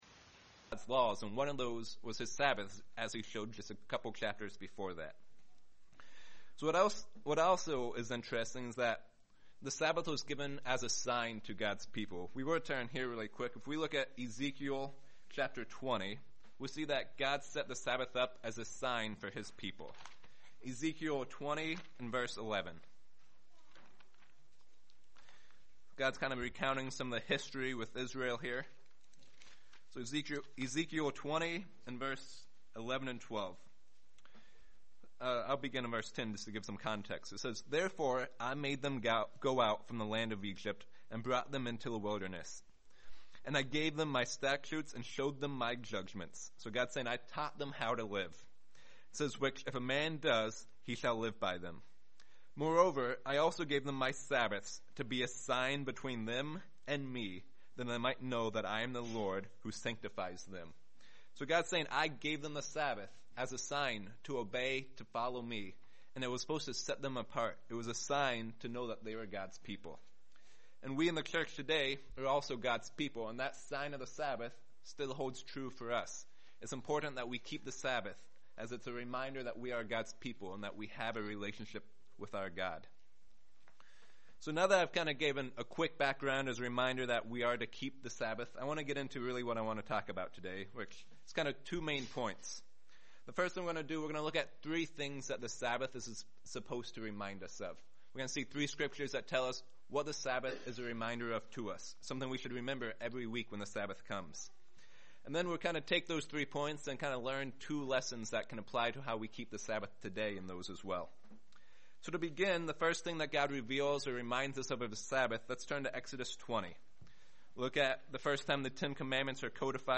Given in Lehigh Valley, PA York, PA
UCG Sermon Studying the bible?